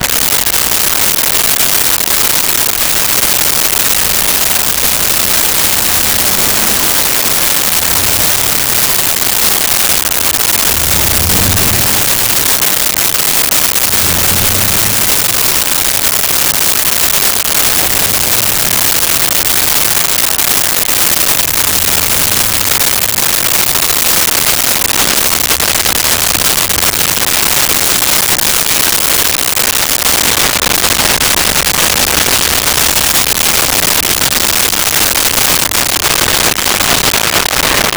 18 Wheeler Away Shift Slow
18 Wheeler Away Shift Slow.wav